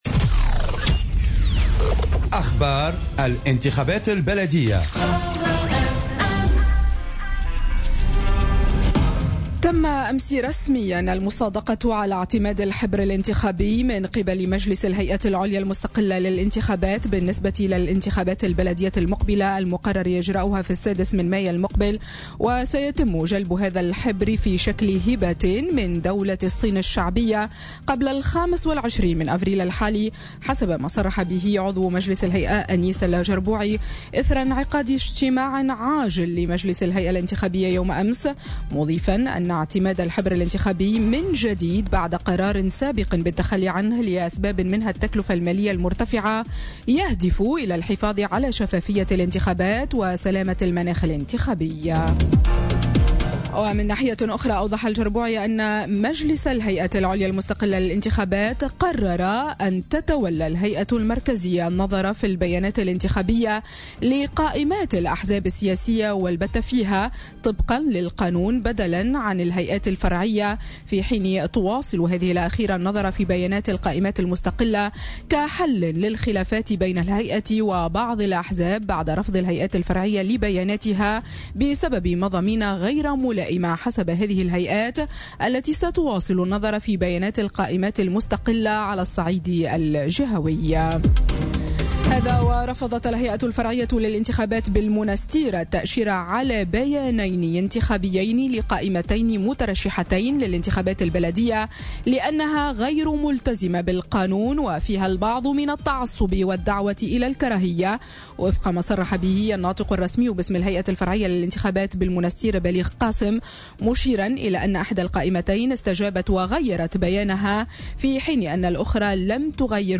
نشرة أخبار السابعة صباحا ليوم الإثنين 16 أفريل 2018